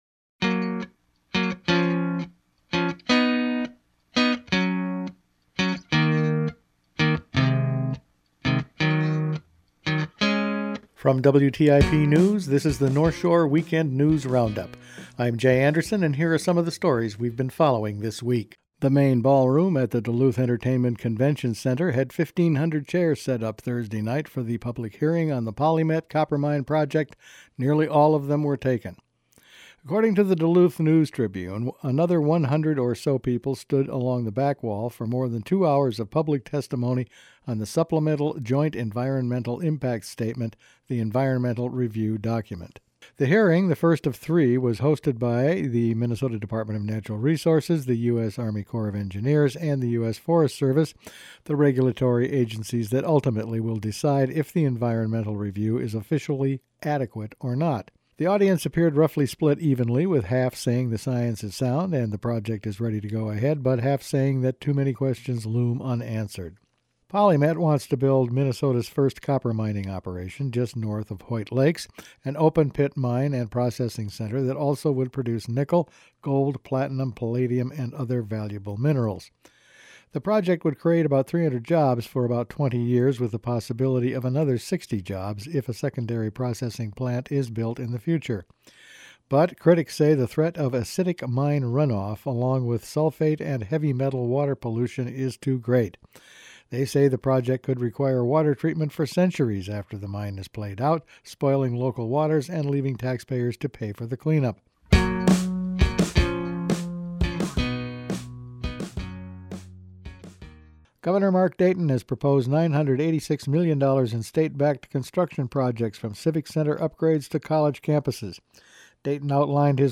Weekend News Roundup for January 18
Each week the WTIP news staff puts together a roundup of the news over the past five days. A big turn out for a PolyMet informational meeting and a setback for shipping tar sands crude on Lake Superior…all in this week’s news.